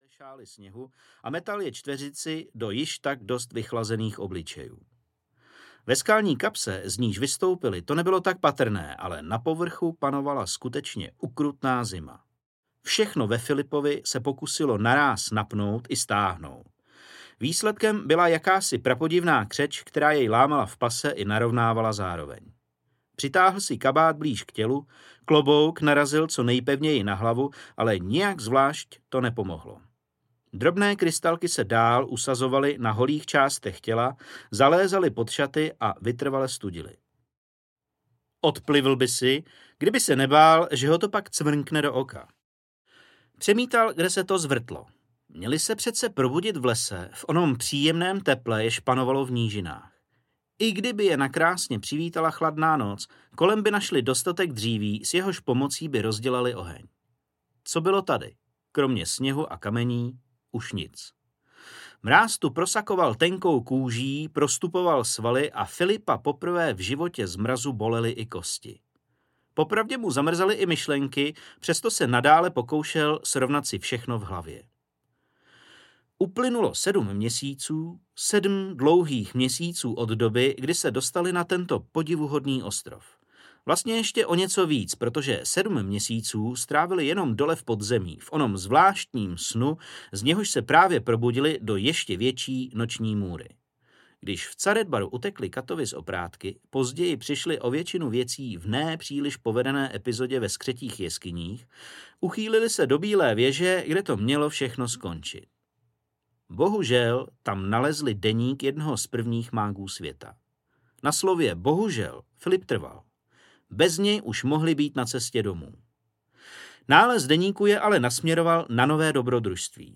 Pouští i větrnou horou, sopkou i ledovými jeskyněmi, tady všude pokračuje dobrodružství nesourodé družiny dobrodruhů, které potměšilý osud dal dohromady, aby zrovna oni zachránili svět.„Nejvtipnější fantasy od dob největšího světového spisovatele, filosofa a myslitele, Terryho Pratchetta.“Natočeno ve studiu Retro Nation.